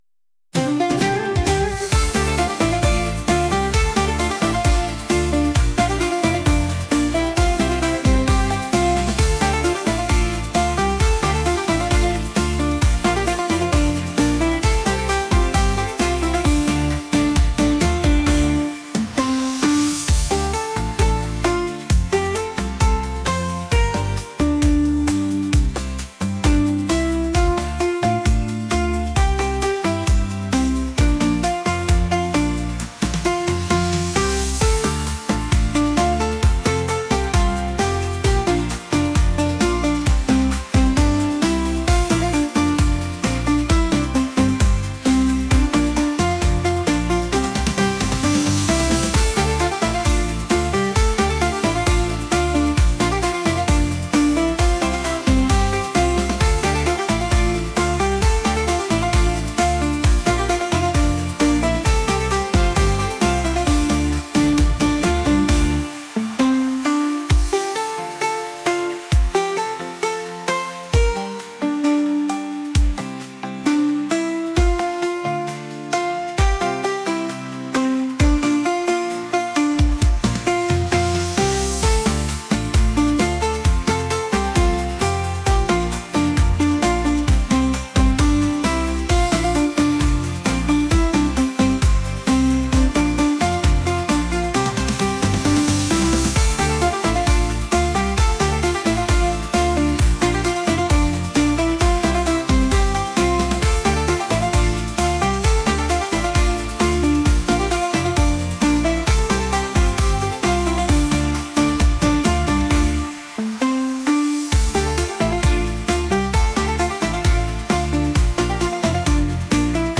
pop | vibes | upbeat